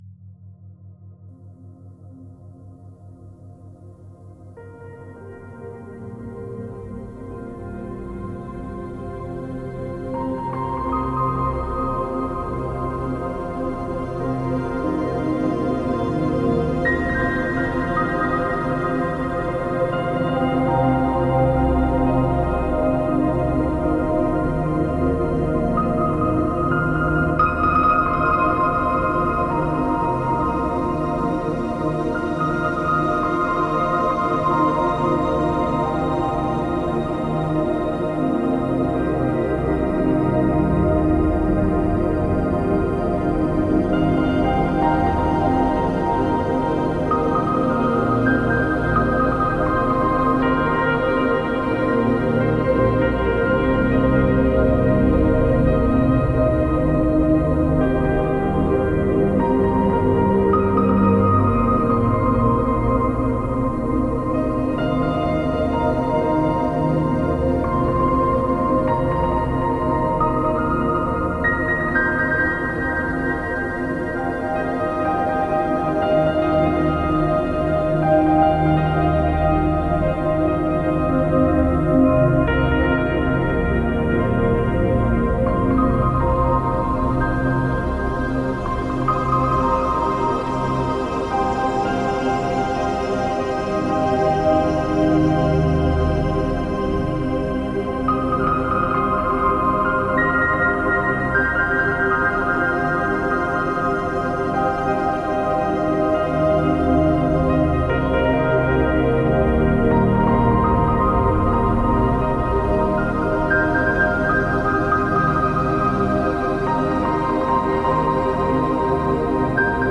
Theta
Beeinflusse deine Gehirnwellen mit Binaural Beats!
Gamma-Theta-Dimensional-Shift-BB-40Hz-5Hz-mit-Ambient-Sound.mp3